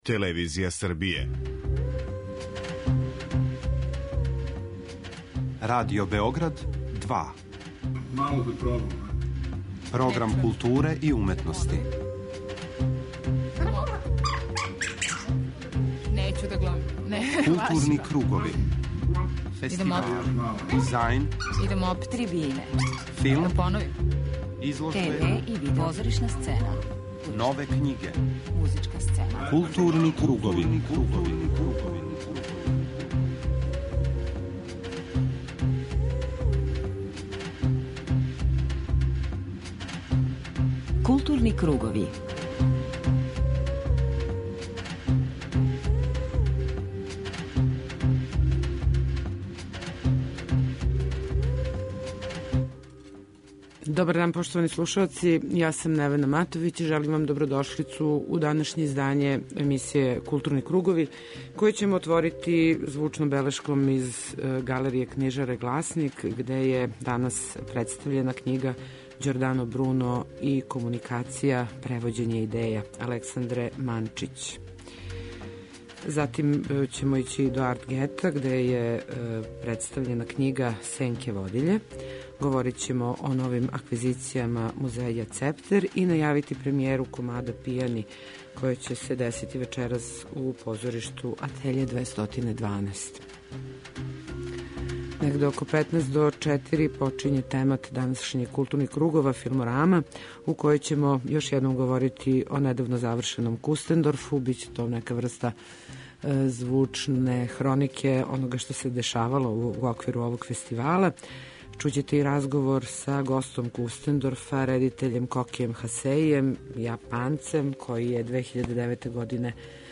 преузми : 41.18 MB Културни кругови Autor: Група аутора Централна културно-уметничка емисија Радио Београда 2.